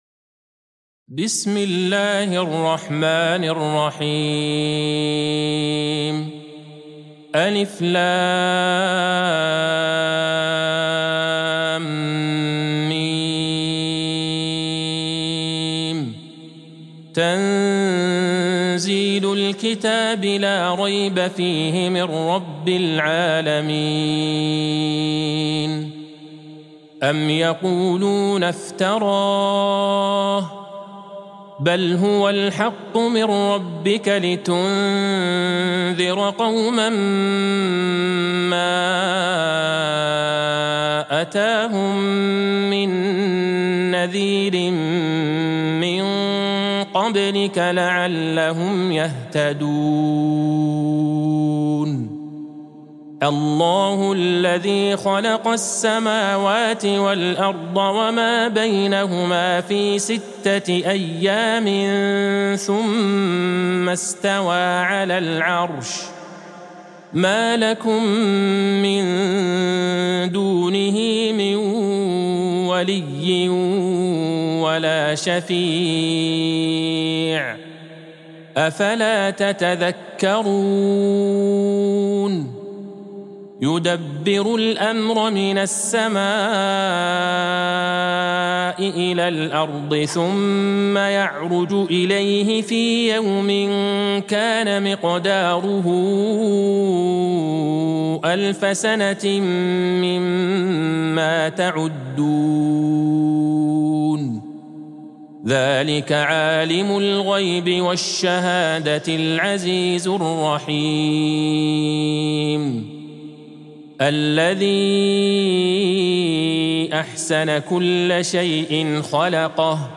سورة السجدة Surat As-Sajdah | مصحف المقارئ القرآنية > الختمة المرتلة ( مصحف المقارئ القرآنية) للشيخ عبدالله البعيجان > المصحف - تلاوات الحرمين